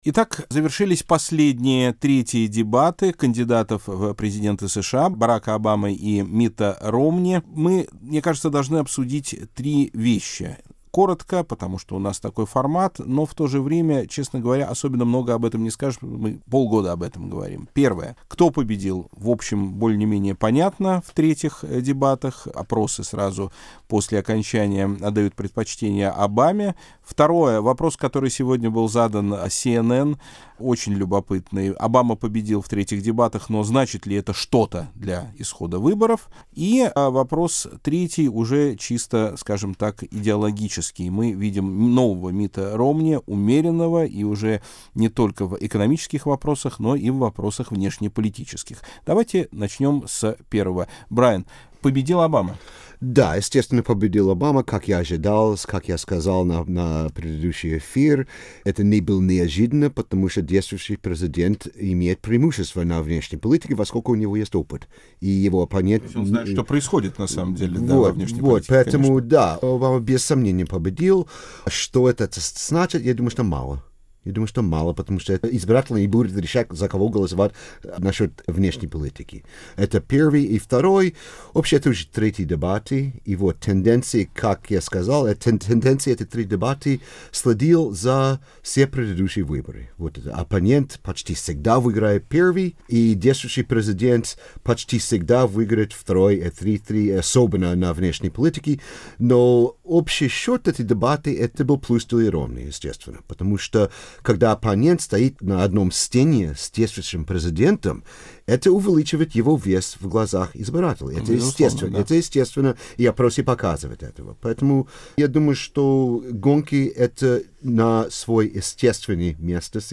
Круглый стол: Третий тур президентских дебатов в США